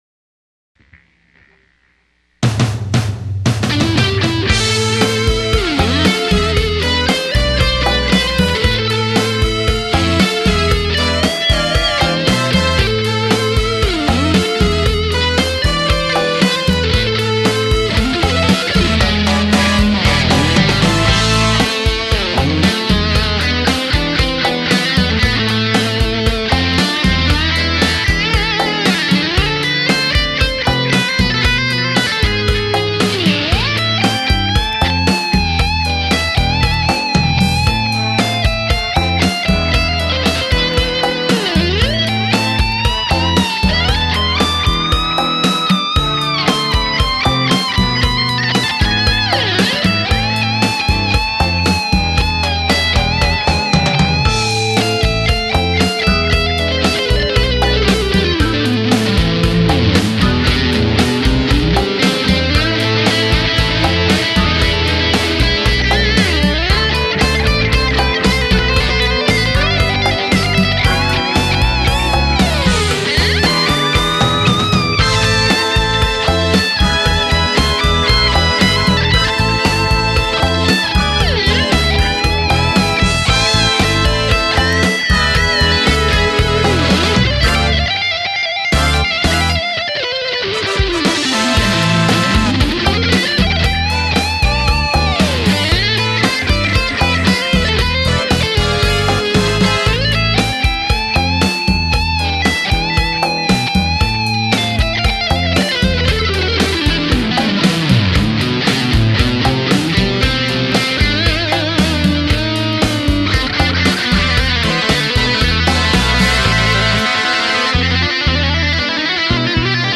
That is some phenomenal guitar playing there!